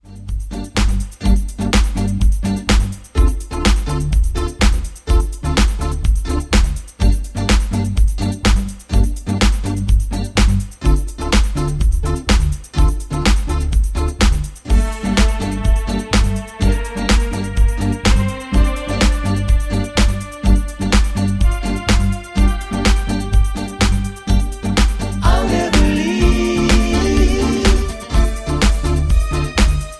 Bb
MPEG 1 Layer 3 (Stereo)
Backing track Karaoke
Pop, 1990s